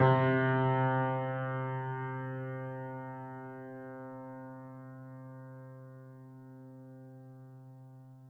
Steinway_Grand
c2.mp3